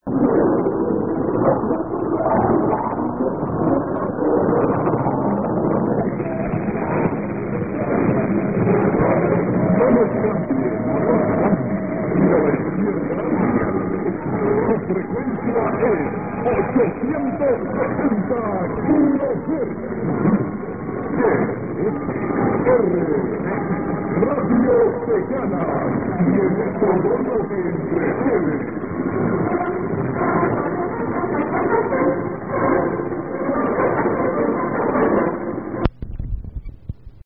All recordings were made in Santa Barbara, Honduras (SB) using a Yaesu FRG-7 receiver, except for the one marked Danli, which was recorded in Danli, Honduras using an ICF-7600 and the internal loop.